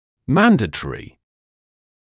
[‘mændətərɪ][‘мэндэтэри]обязательный